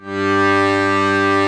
G1 BUTTON -R.wav